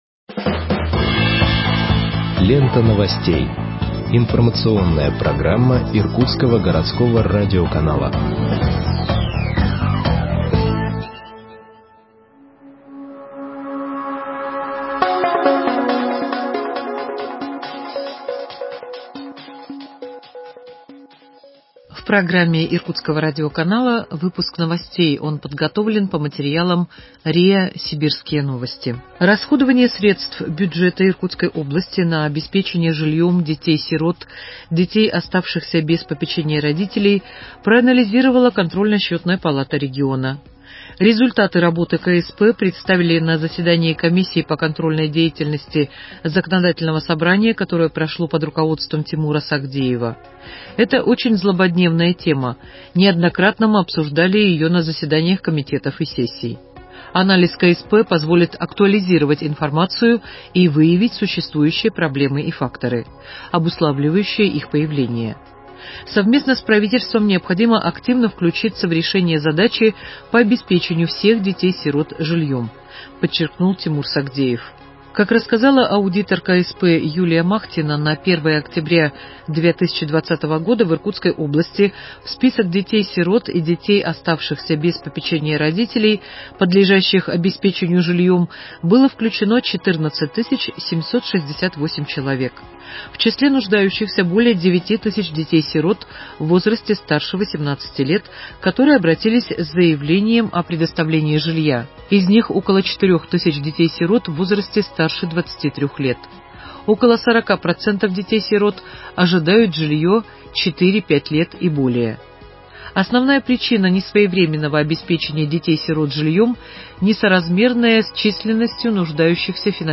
Выпуск новостей в подкастах газеты Иркутск от 19.05.2021 № 2